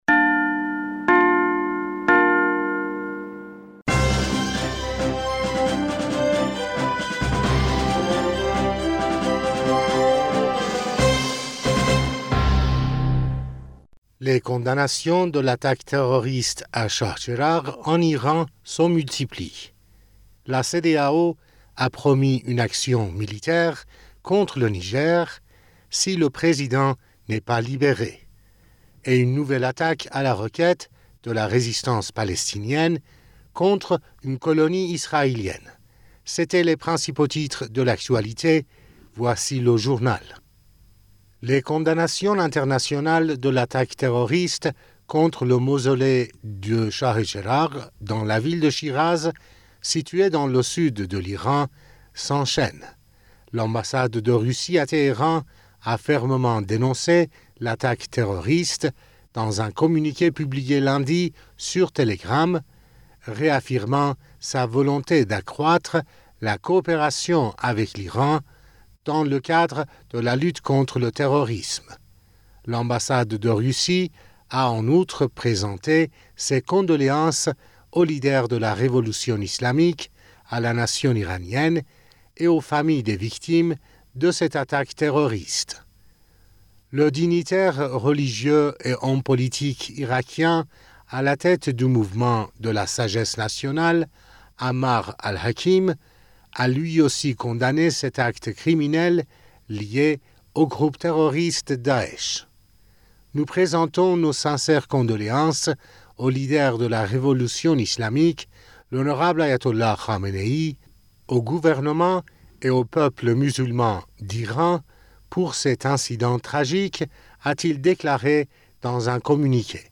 Bulletin d'information du 15 Aout 2023